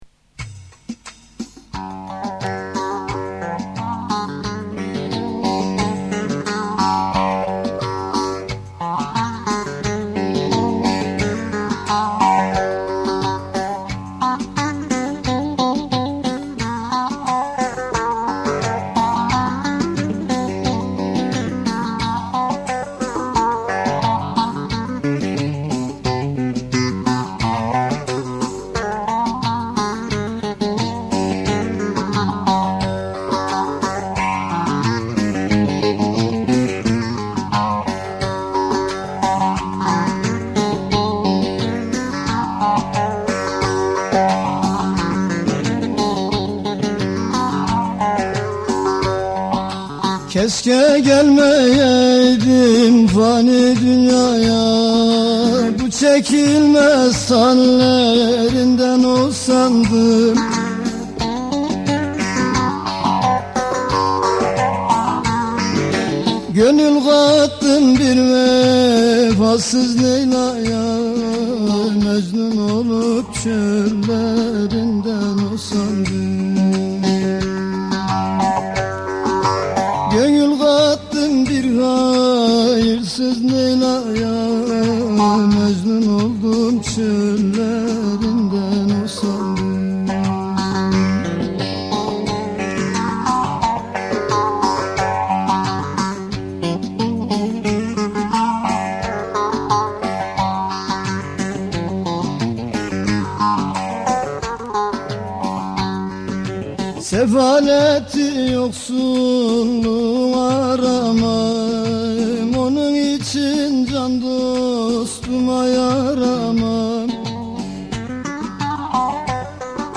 Anadolu' dan Türküler